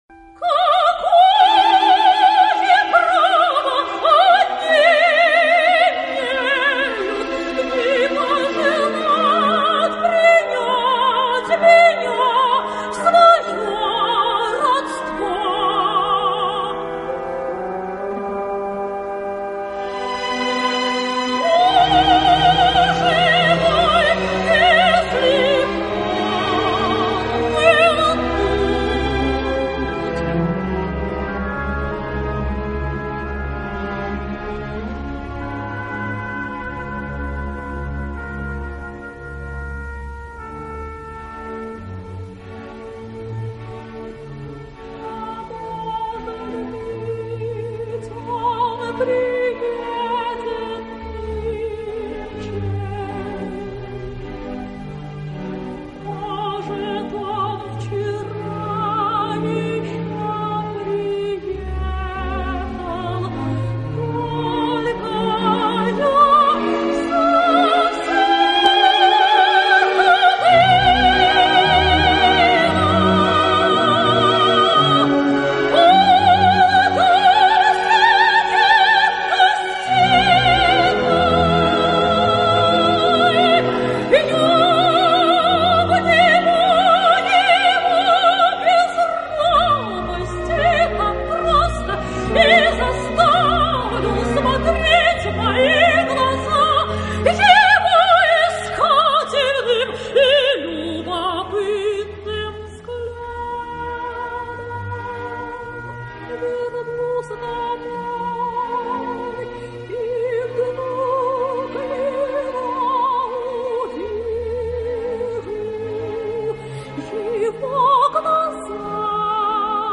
Ария